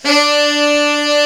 Index of /90_sSampleCDs/Giga Samples Collection/Sax/SAXIBAL
TENOR HARD D.wav